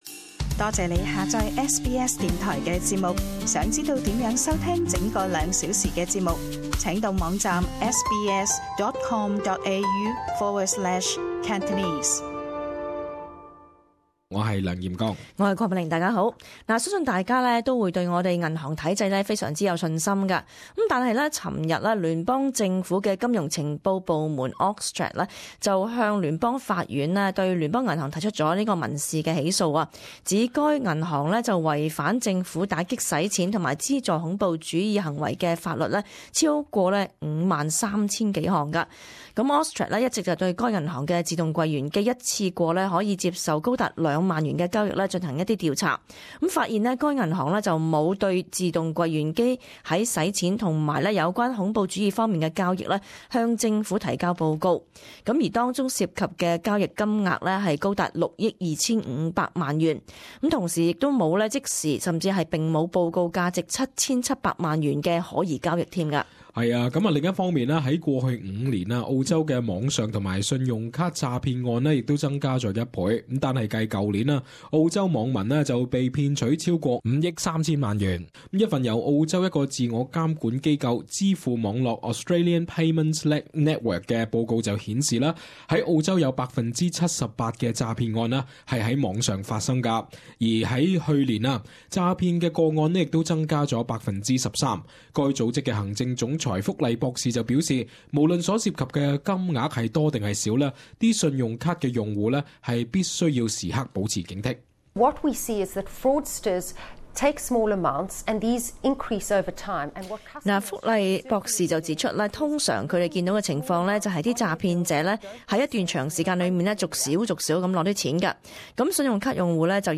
【時事報導】澳洲網上及信用卡詐騙五年增一倍